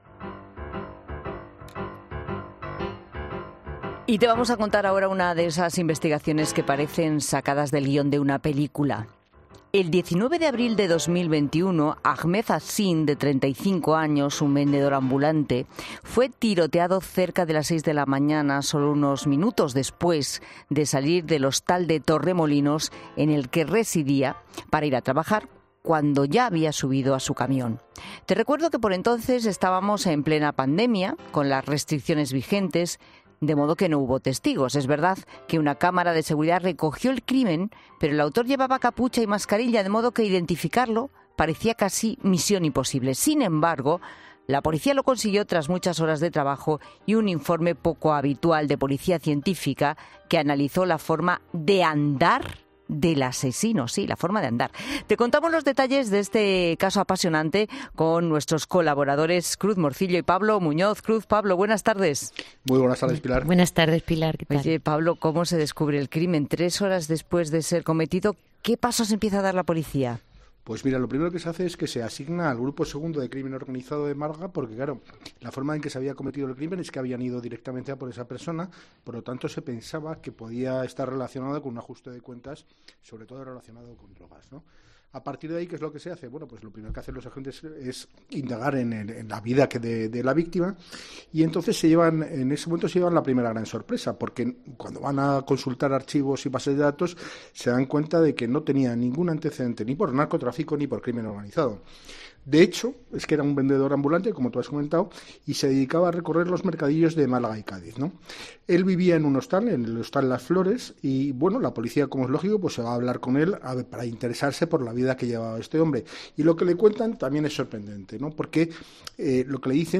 Los colaboradores de La Tarde